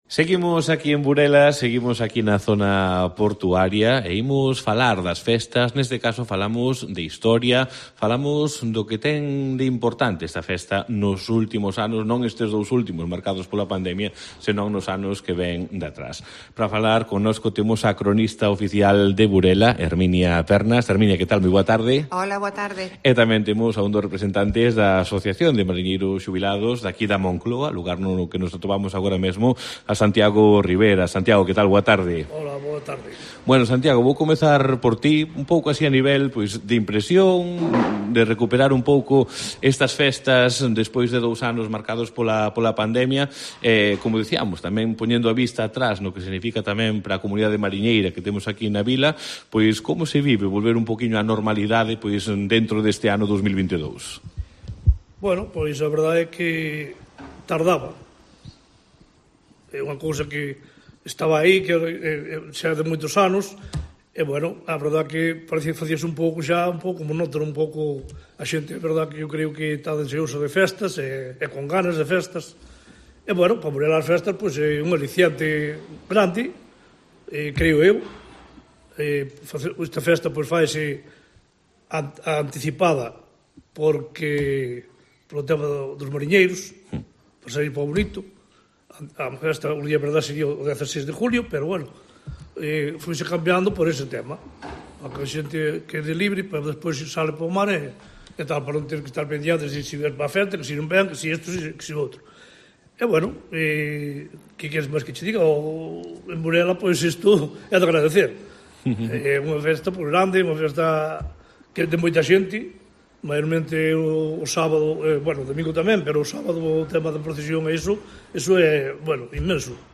los marineros jubilados de A Moncloa compartieron en los micrófonos de COPE de la Costa vivencias y anécdotas de las patronales